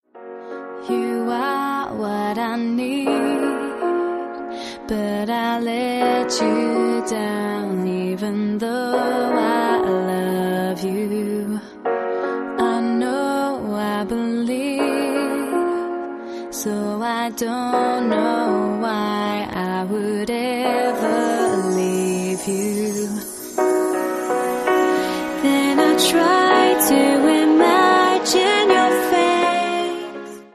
mitreißender und mitsingbarer
• Sachgebiet: Pop